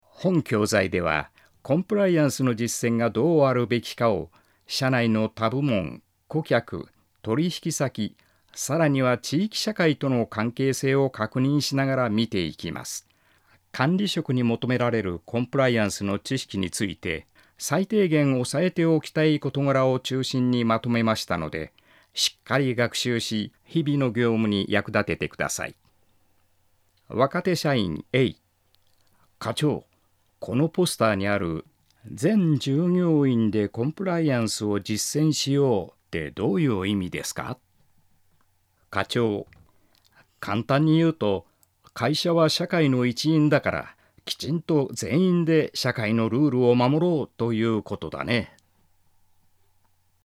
male40_11.mp3